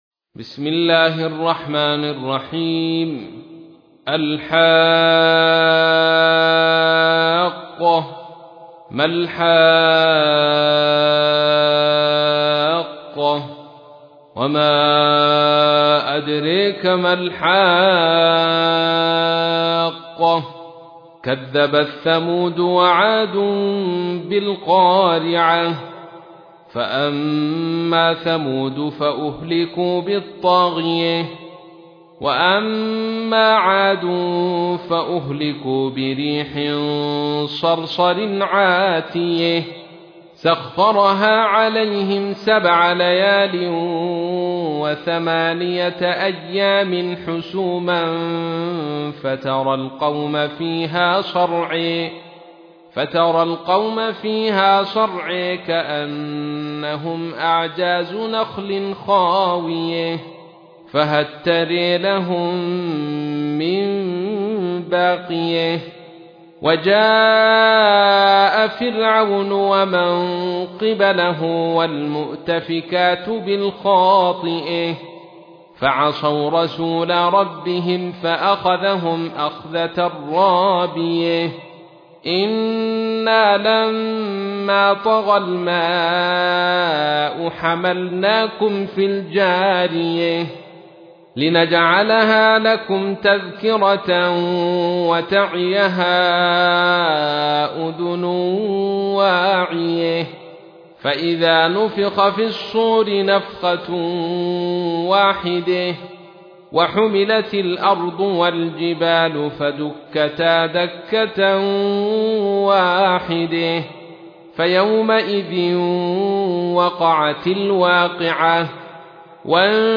تحميل : 69. سورة الحاقة / القارئ عبد الرشيد صوفي / القرآن الكريم / موقع يا حسين